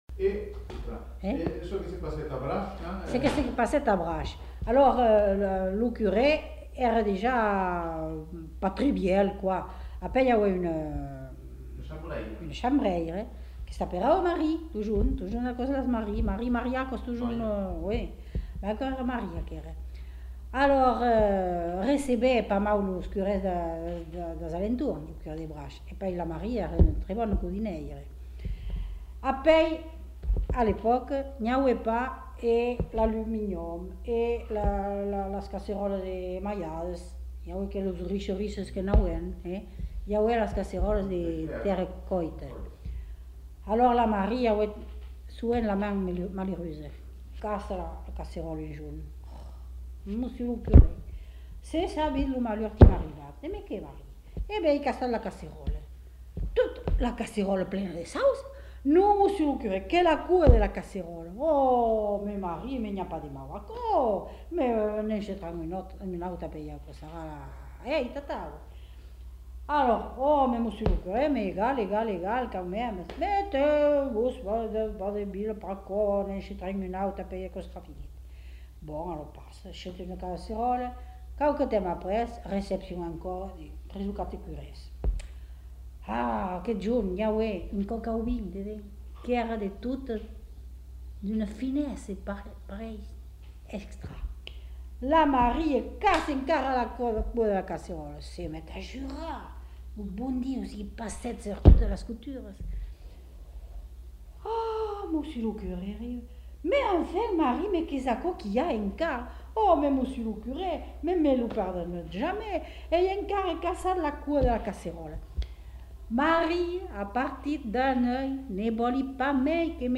Lieu : Moulis-en-Médoc
Genre : conte-légende-récit
Effectif : 1
Type de voix : voix de femme
Production du son : parlé